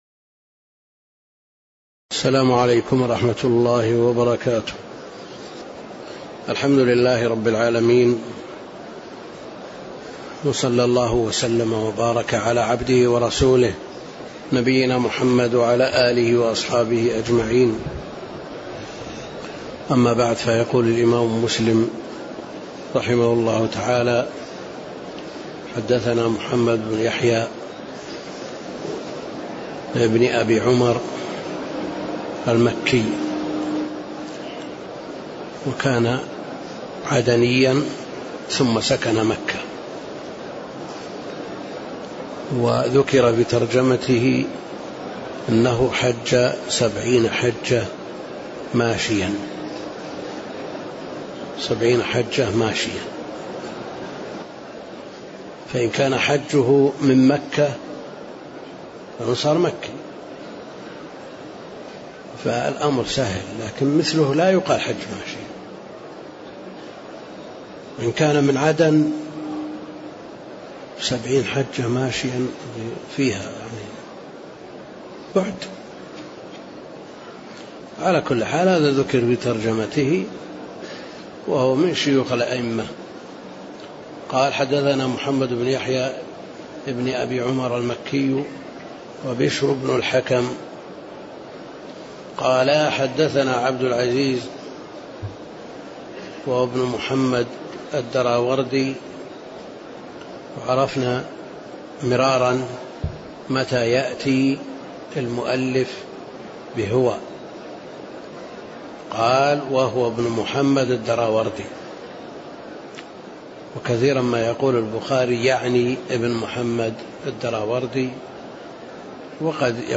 شرح كتاب الإيمان من صحح مسلم وفيه: باب الدليل على أن من رضي بالله ربًا فيه: حديث "ذاق حلاوة الإيمان.."
تاريخ النشر ٢١ ذو القعدة ١٤٣٤ المكان: المسجد النبوي الشيخ: فضيلة الشيخ د. عبدالكريم الخضير فضيلة الشيخ د. عبدالكريم الخضير باب الدليل على أن من رضي بالله ربًا..- من حديث "ذاق حلاوة الإيمان.."